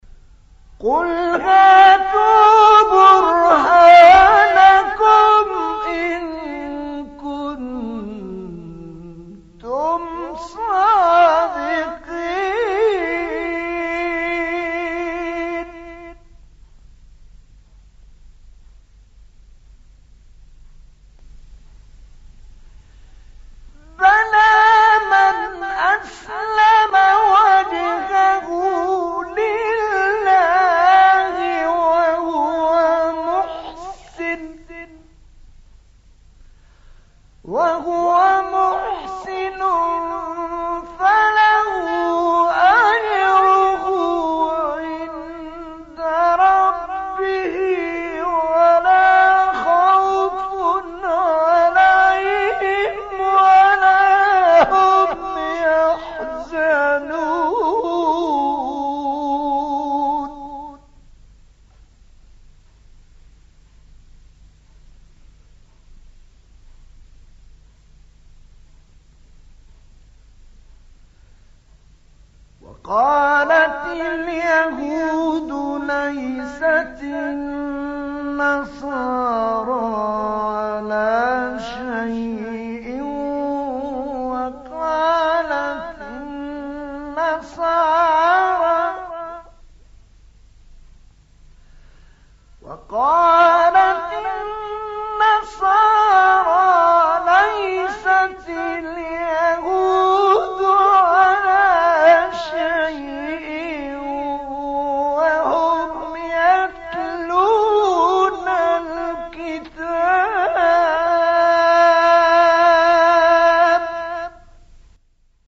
مقام نهاوند طه‌ الفشنی | نغمات قرآن | دانلود تلاوت قرآن